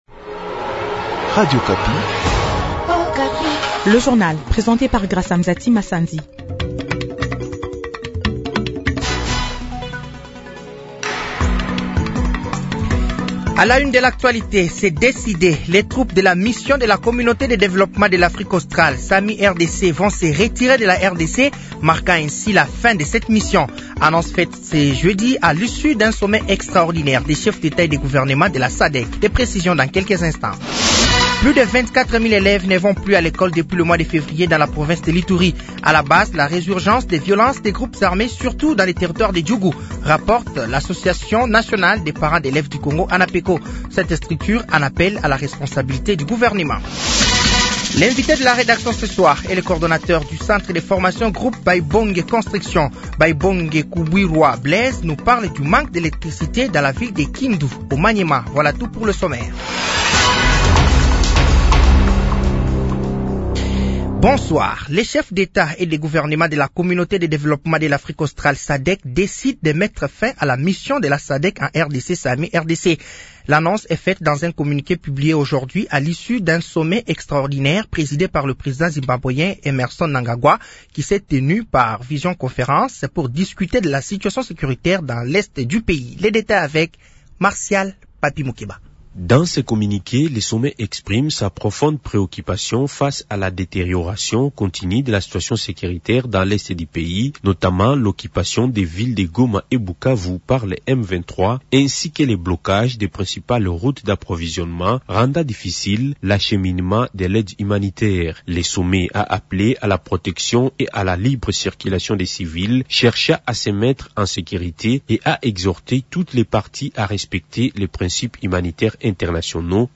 Journal français de 18h de ce jeudi 13 mars 2025